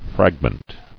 [frag·ment]